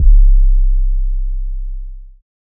Southside 808 (16).wav